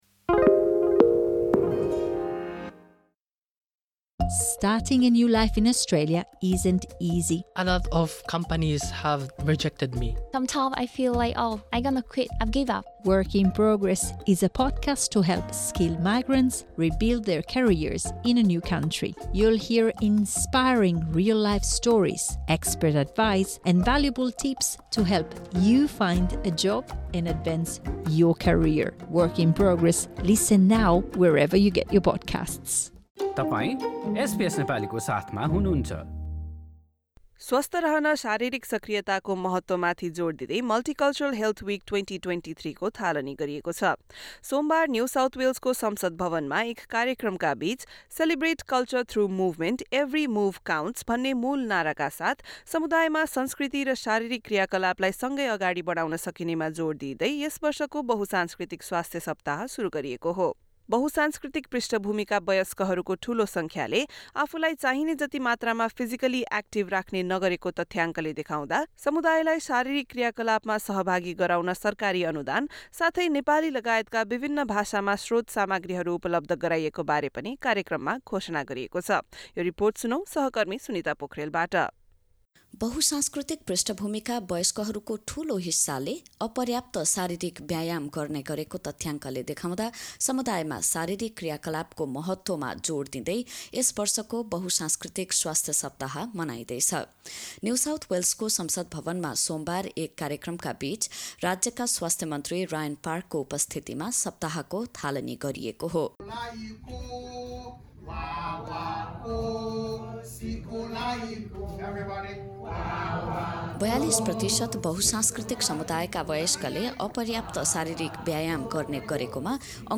कार्यक्रममा बहु-सांस्कृतिक पृष्ठभूमिका ४२ प्रतिशत मानिसहरूले चाहिने जति शारीरिक कसरत नगरेको अध्ययनहरूले देखाएको बताइएको थियो। एक रिपोर्ट।